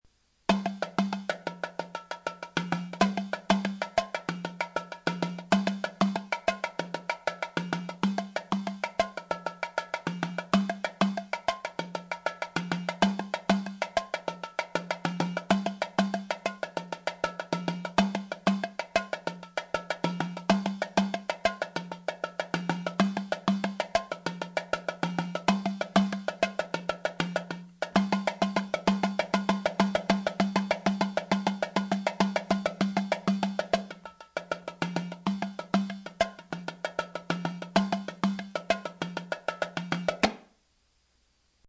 A festival drum from Morocco used in the religious holiday Achoura.
These small clay drums 10 inches tall have a string stretched under the head which causes the drum to buzz when struck , as in a snare drum.
tarija.mp3